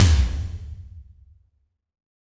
HEXTOMLW1.wav